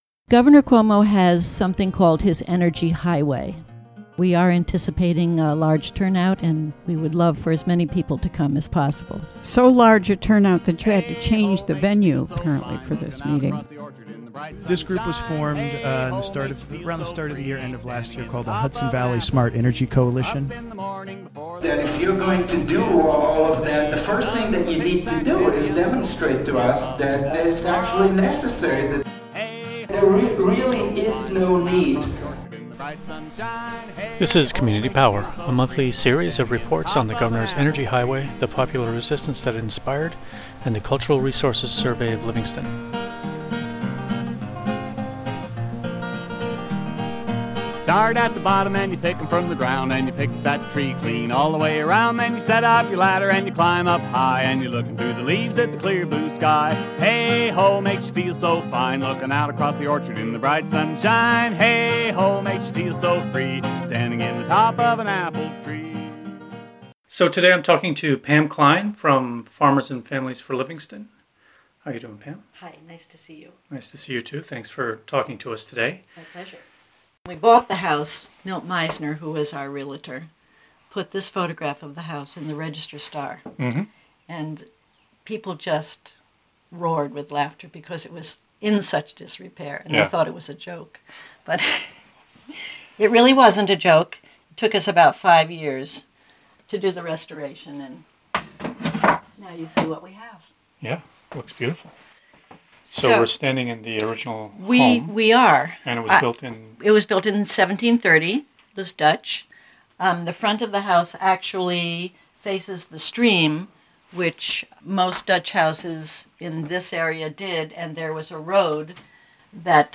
This segment is an installment of "Community Power," a year-long, monthly series of interviews about Governor Andrew M. Cuomo's New York Energy Highway Blueprint, the local response to that initiative, and the Cultural Resources Survey of Livingston.